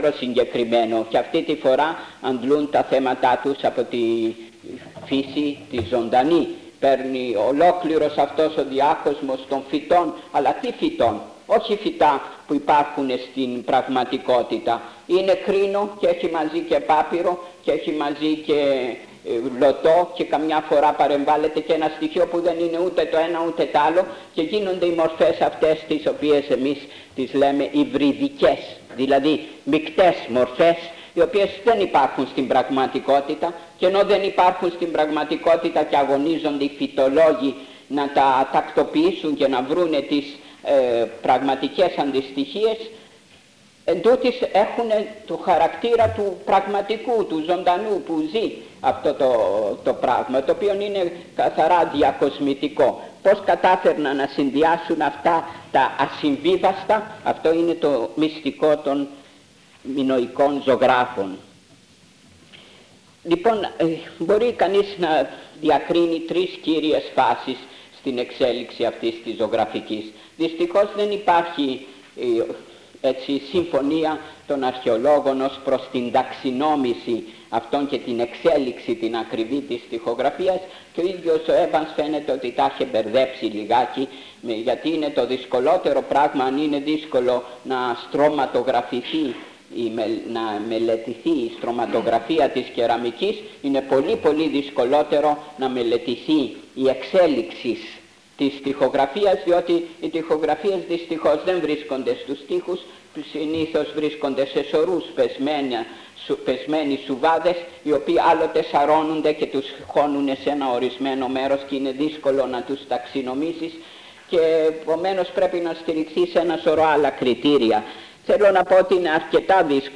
Εξειδίκευση τύπου : Εκδήλωση
Περιγραφή: Κύκλος Μαθημάτων